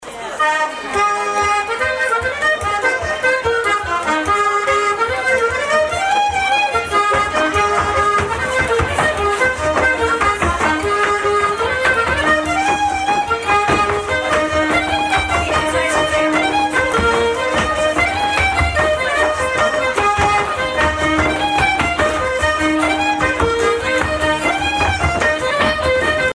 set dance (Irlande)